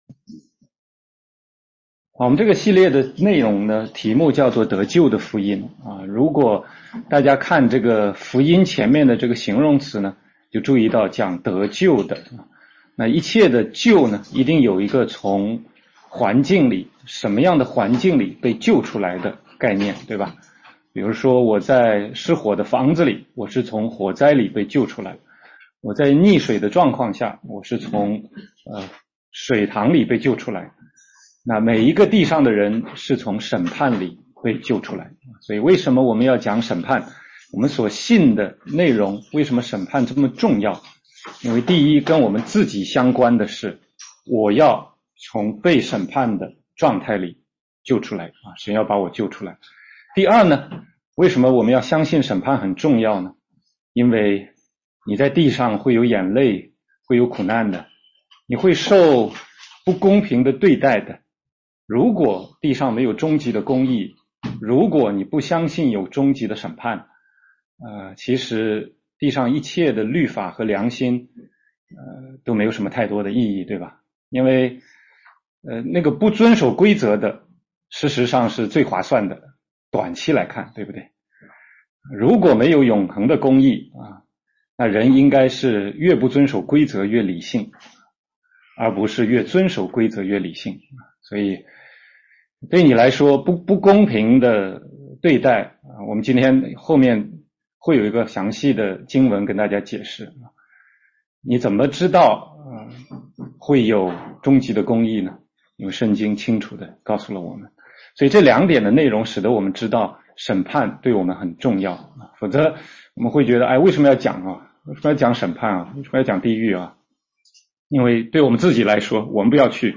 16街讲道录音 - 得救的福音第四讲：神审判